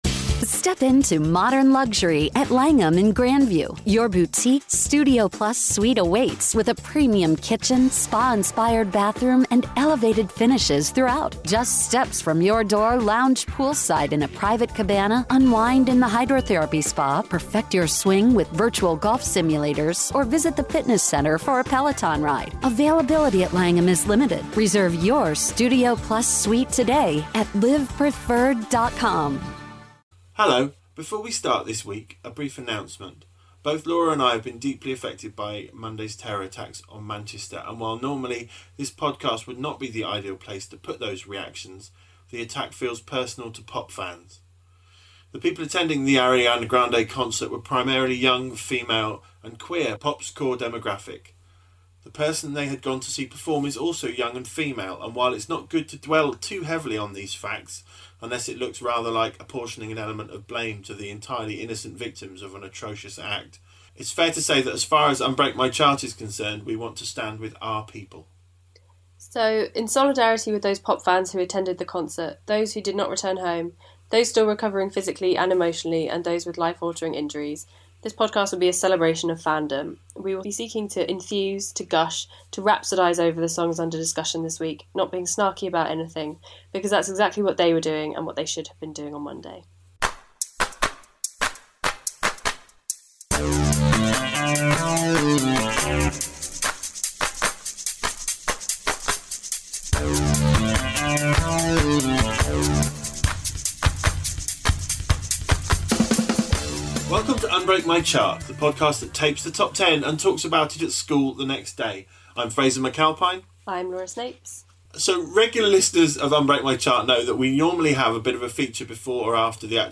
This week, in an act of fannish solidarity with everyone who has been affected by the Manchester bombing, we're adopting a positive, gushing tone, putting all moans to one side and celebrating Great Things in pop music.